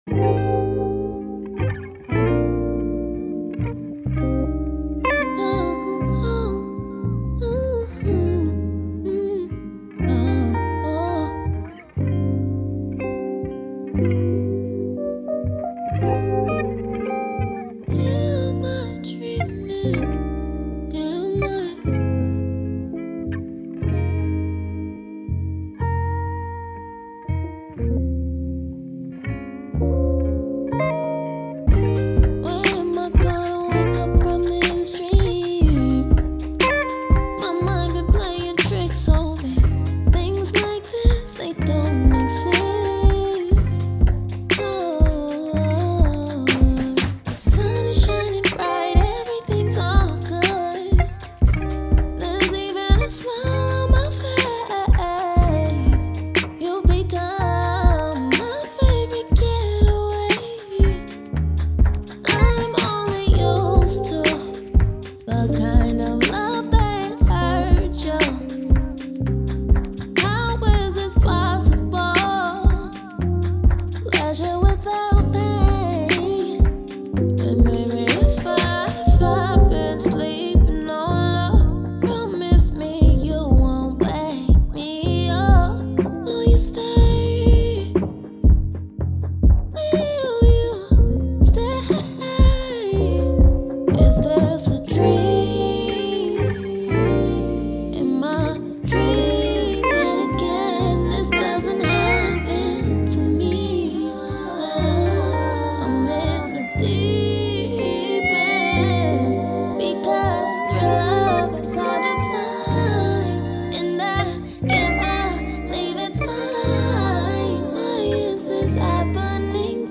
R&B
C Minor